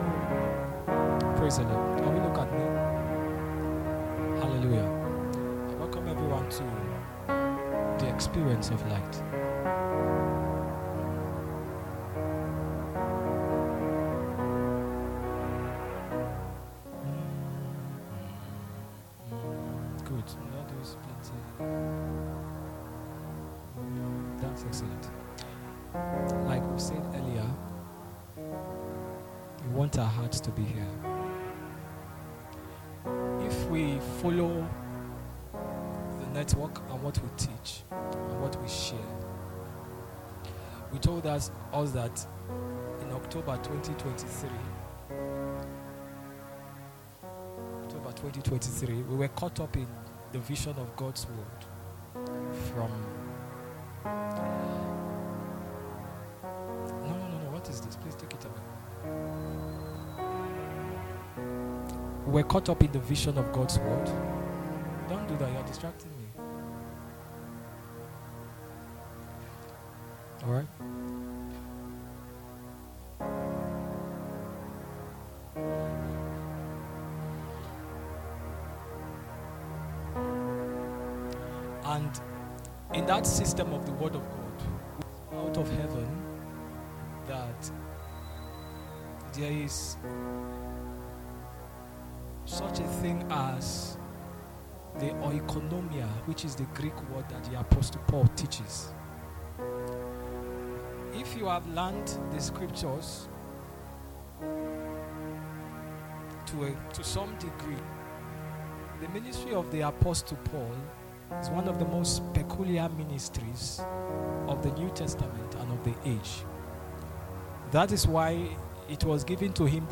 audio teaching